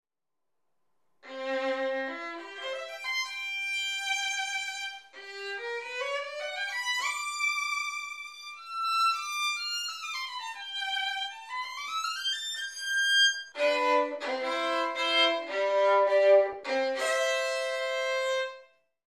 Exploring a wonderful bow by Tourte, from the collection of Charles Beare, with the help of Pierre Baillot.
Peter Sheppard Skaerved-WORKSHOP RECORDINGS